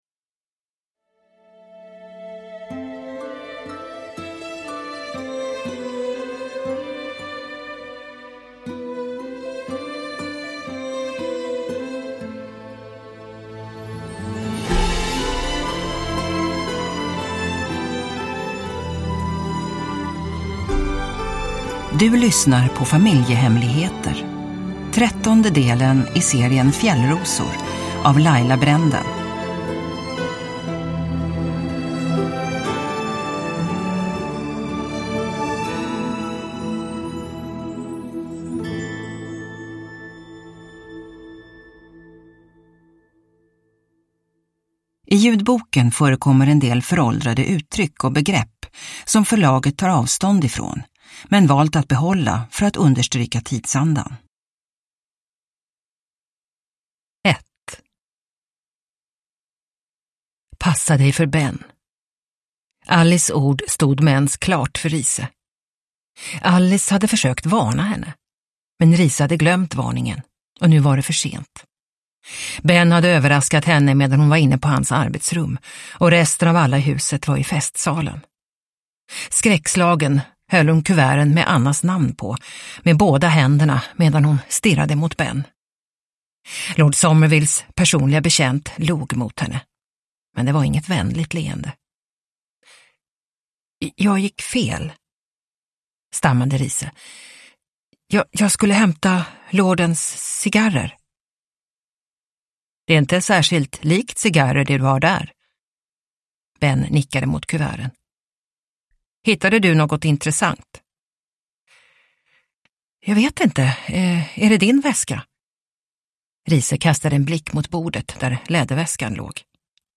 Familjehemligheter (ljudbok) av Laila Brenden